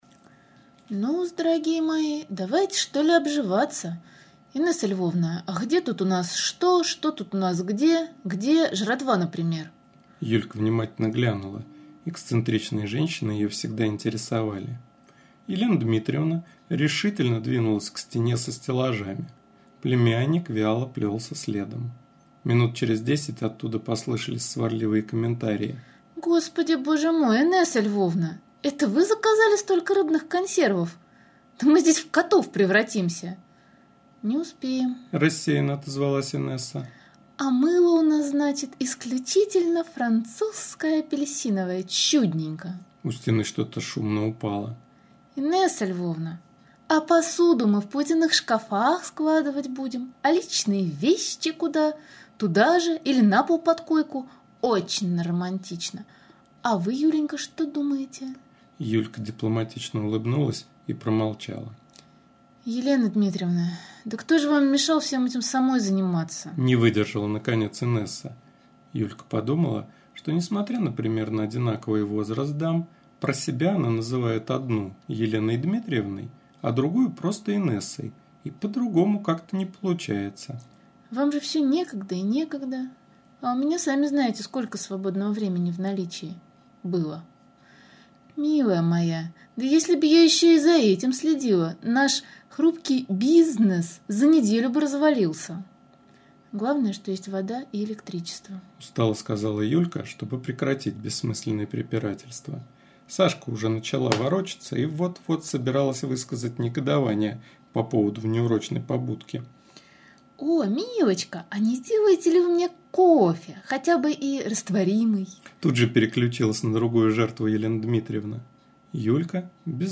Аудиокнига "Nе наша планета", .mp3